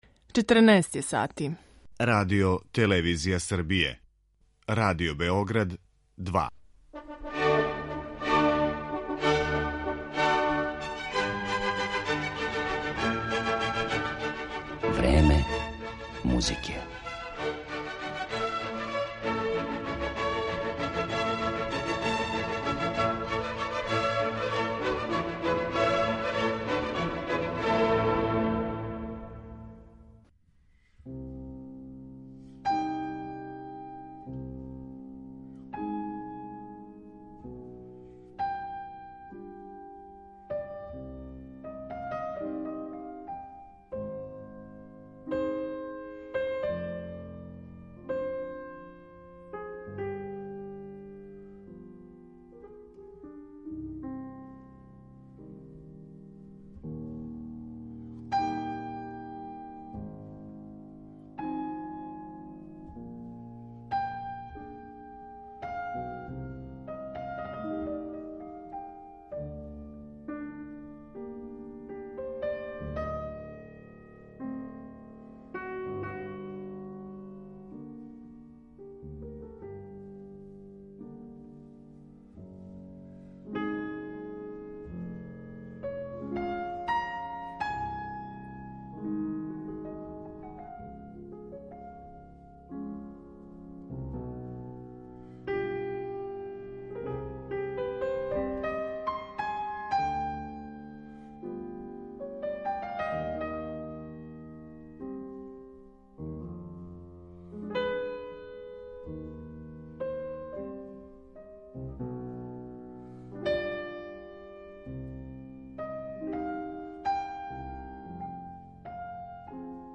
Усредсређен на капитална дела европске музичке заоставштине, Погорелић нам у време све већег засићења традиционалних тумачења пружа прилику за нов сусрет са музиком, која у његовим тумачењима звучи оригинално, свеже и са јаким емотивним набојем. Његов јединствени извођачки приступ биће скициран интерпретацијама дела Шопена, Мусоргског, Шумана, Скарлатија и Баха, уз кратак интервју са пијанистом.